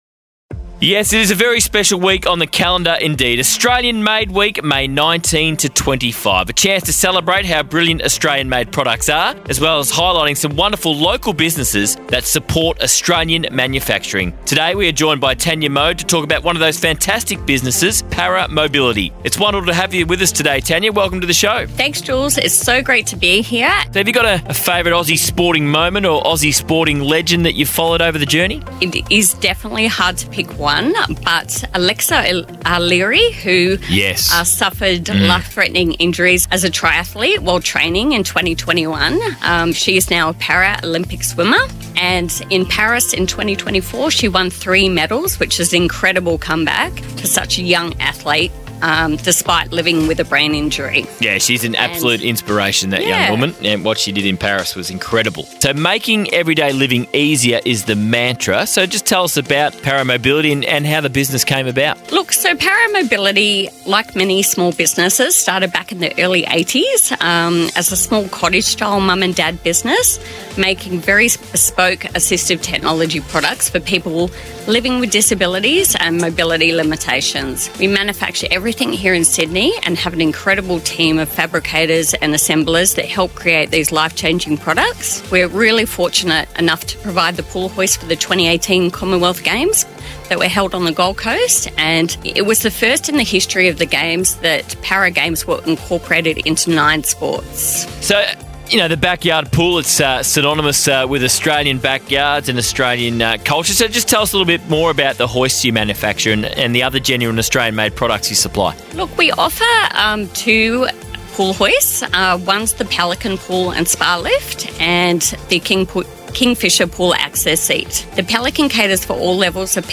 Para Mobility was recently featured in a national radio interview with the Sports Entertainment Network (SEN) as part of Australian Made Week celebrations.